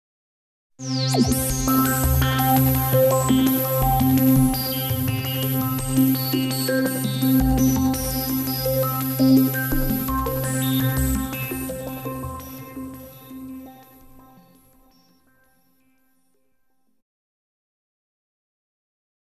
04_specialsfx_2_SQ.wav